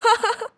sura_cheer2.wav